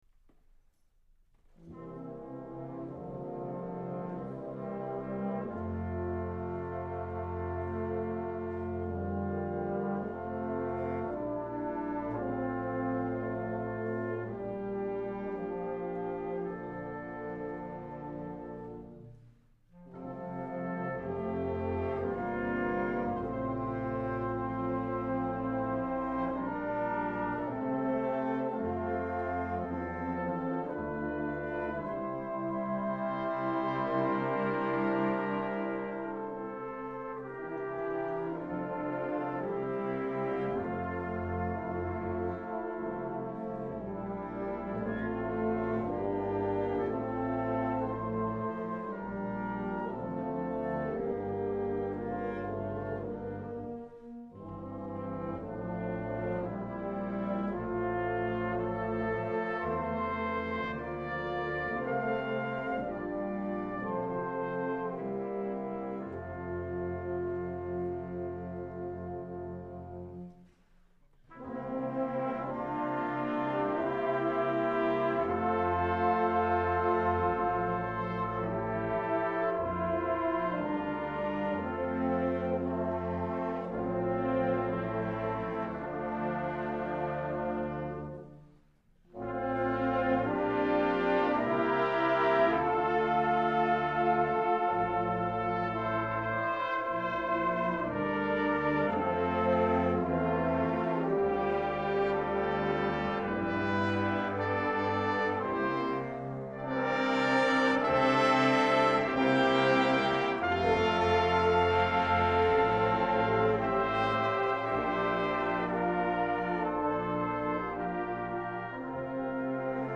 Irish Tune from County Derry (4.5MB MP3), a Percy Grainger piece played by the Stanford Wind Ensemble.